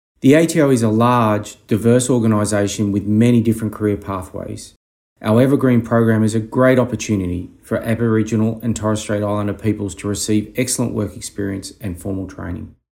The following recordings feature ATO Deputy Commissioner and Indigenous Champion, Matthew Hay, discussing the 2022 Evergreen program.